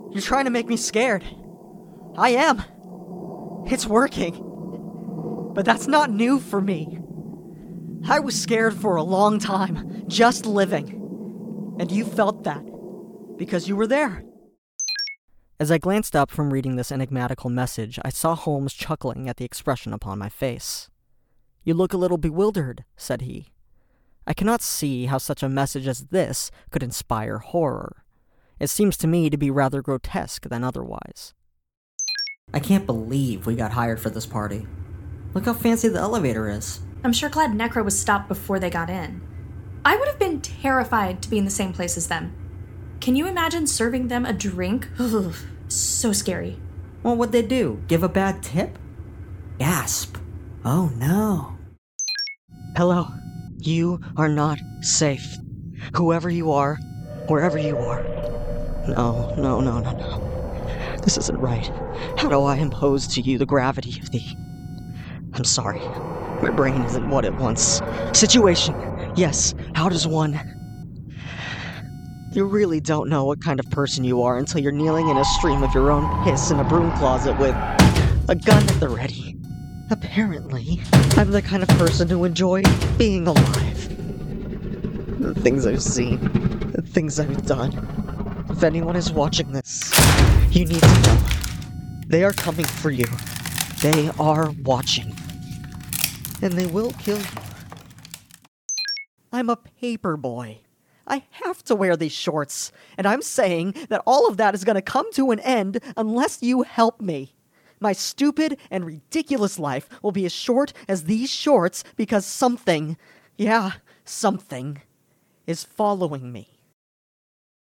two minute voice reel